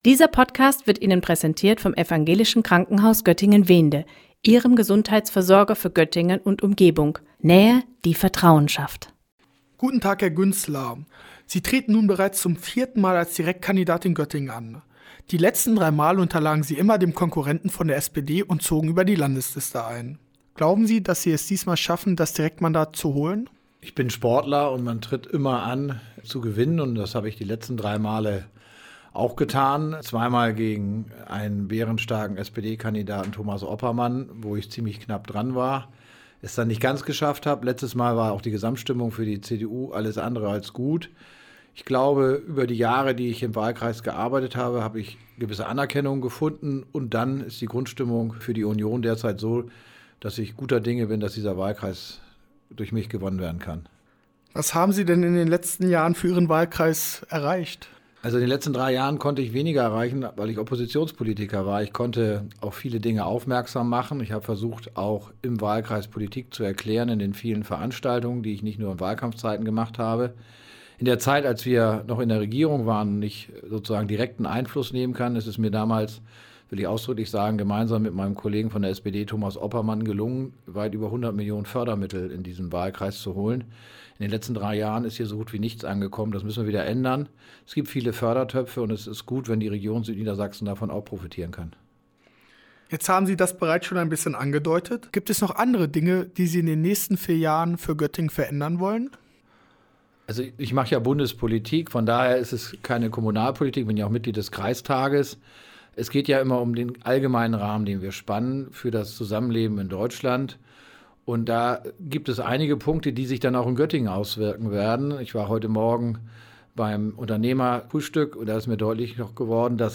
- CDU Direktkandidat Fritz Güntzler im Interview - StadtRadio Göttingen